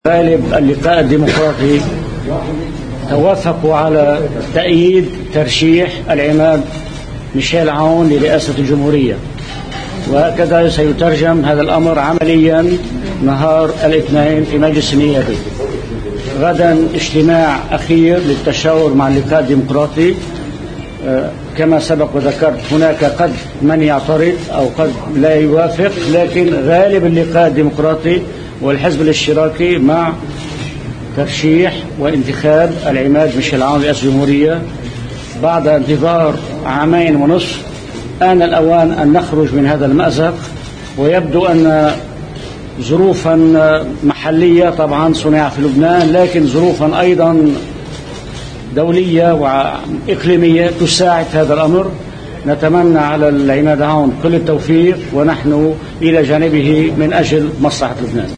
رئيس الحزب الإشتراكي واللقاء الديمقراطي النائب وليد جنبلاط بعيد لقائه العماد ميشال عون في كليمنصو: